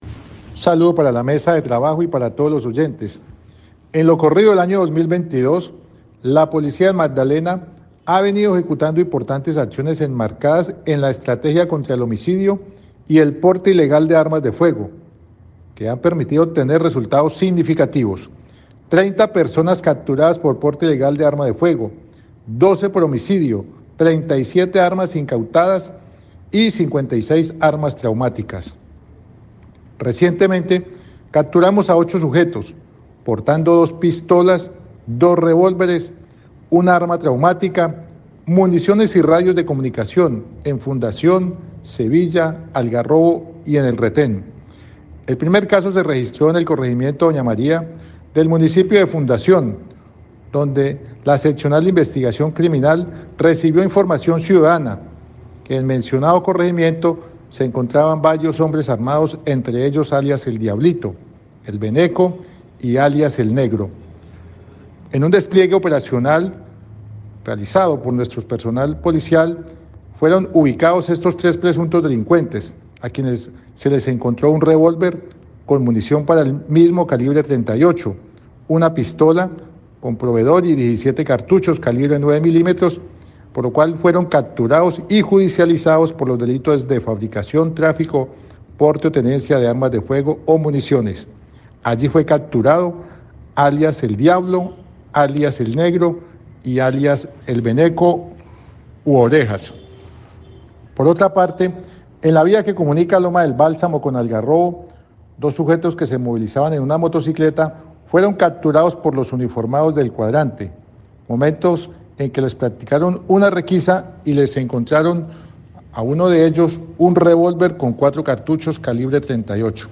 Sostuvo el coronel Andrés Serna Bustamante, comandante del Departamento de Policía Magdalena.